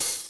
HI HAT I.wav